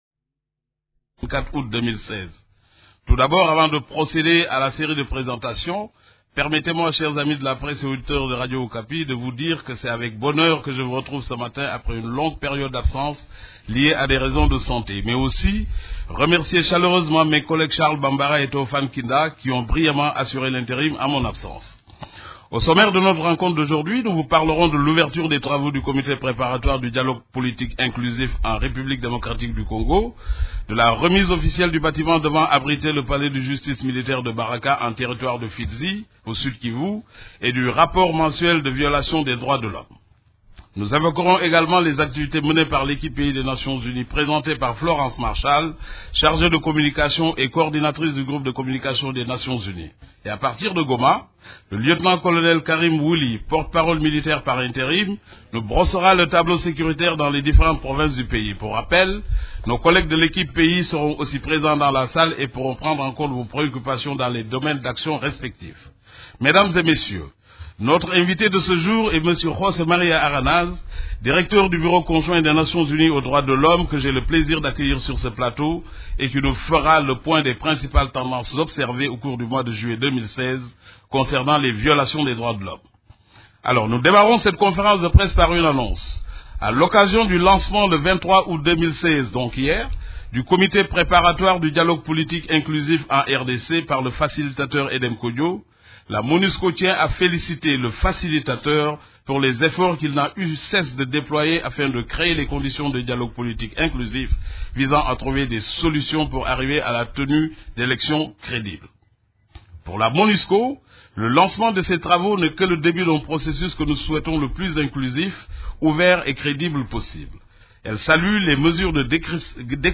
Conférence de presse du 24 août 2016
La conférence de presse hebdomadaire des Nations unies du mercredi 24 août à Kinshasa a porté sur les activités des composantes de la MONUSCO, les activités de l’équipe-pays et la situation militaire.